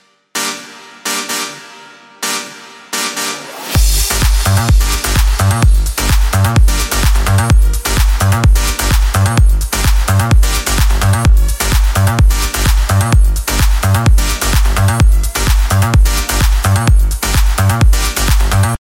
без слов
house
Groove
Простая нарастающая музыка